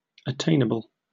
Ääntäminen
IPA : /əˈteɪnəbəl/